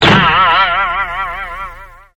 Cartoon - Boing.mp3